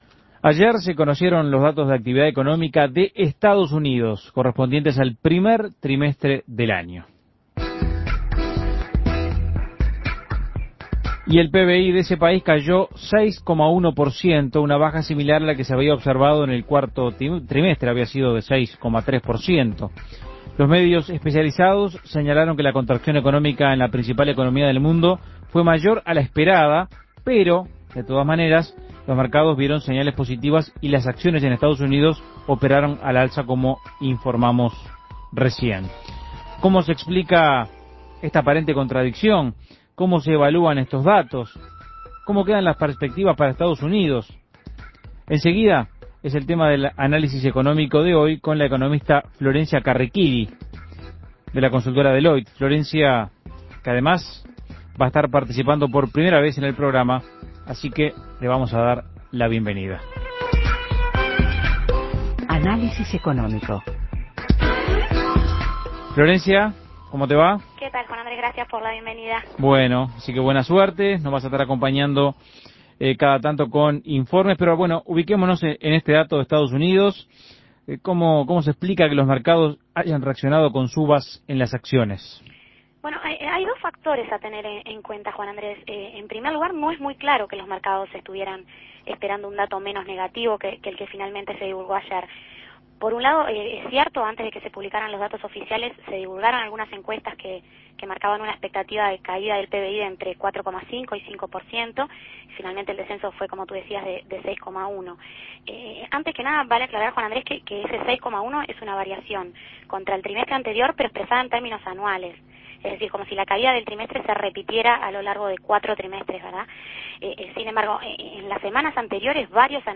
Análisis Económico ¿Qué perspectivas se abren para la economía de EEUU?